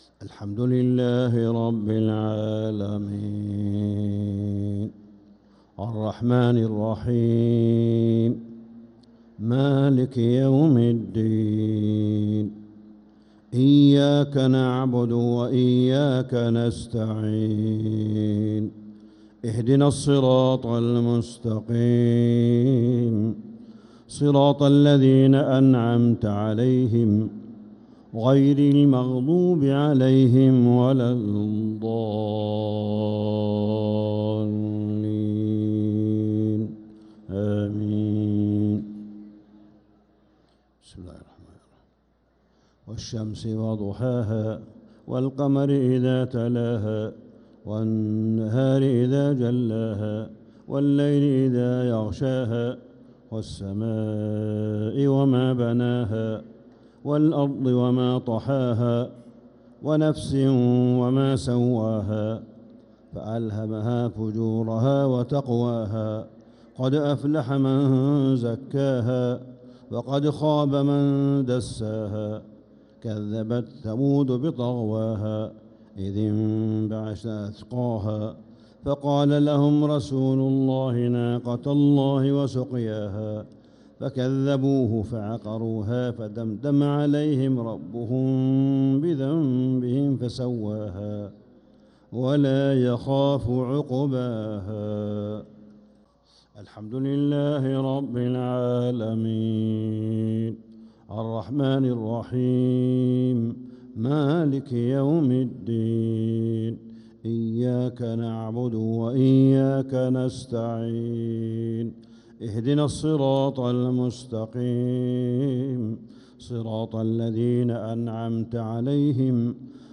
عشاء السبت 1-9-1446هـ سورتي الشمس و العاديات كاملة | Isha prayer Surat ash-Shams & al-`Adiyat 1-3-2025 > 1446 🕋 > الفروض - تلاوات الحرمين